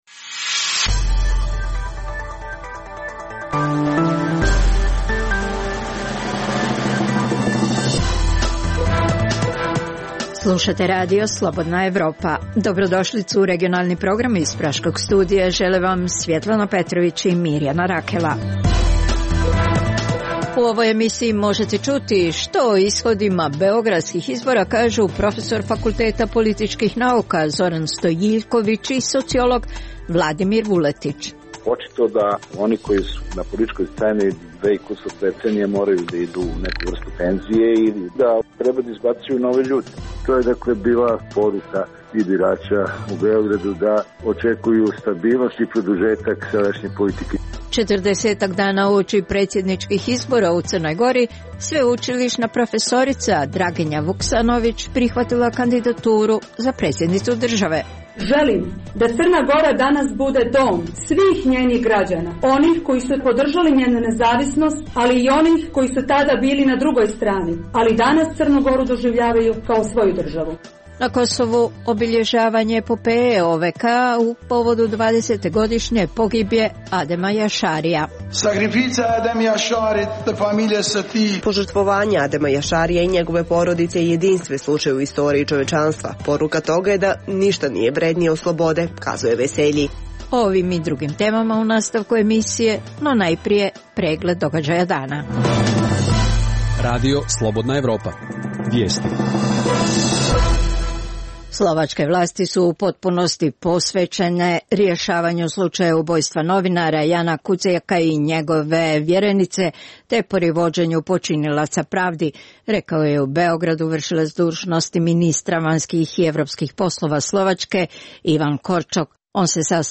Reportaže iz svakodnevnog života ljudi su takođe sastavni dio “Dokumenata dana”.